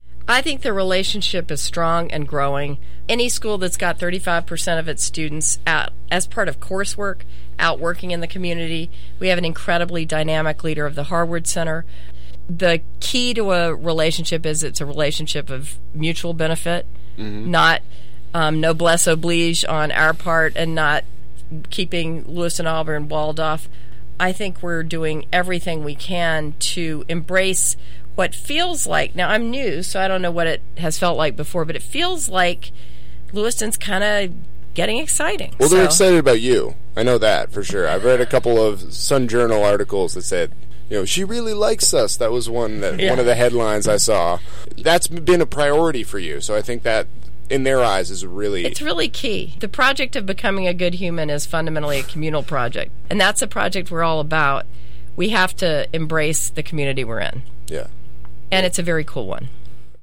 Here’s a brief video of the Feb. 10 event and, below, a selection of edited audio clips from the interview.